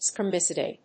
音節sper・mi・ci・dal 発音記号・読み方
/sp`ɚːməsάɪdl(米国英語), sp`əːməsάɪdl(英国英語)/